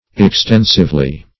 Extensively \Ex*ten"sive*ly\, adv.